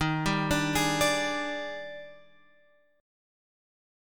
D# Major 11th